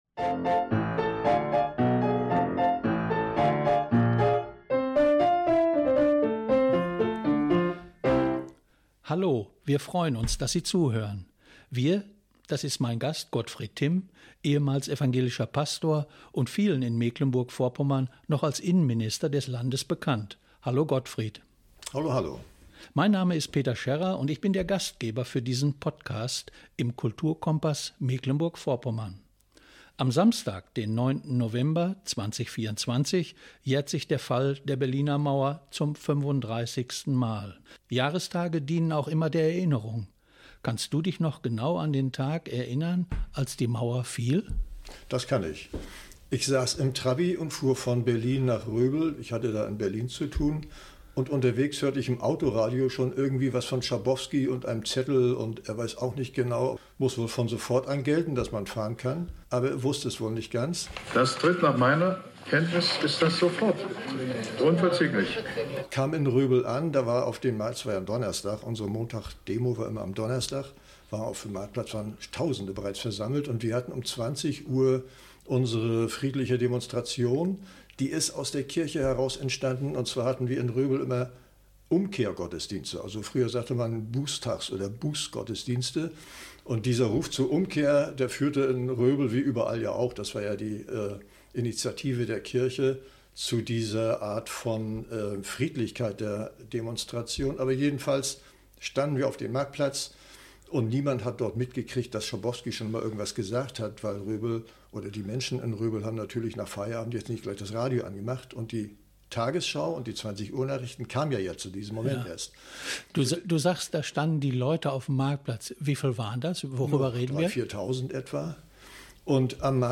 Kulturkompass MV 35 Jahre Mauerfall - mit Gottfried Timm im Gespräch Play Episode Pause Episode Mute/Unmute Episode Rewind 10 Seconds 1x Fast Forward 30 seconds 00:00 / 1:36 Abonnieren Teilen RSS Feed Teilen Link Embed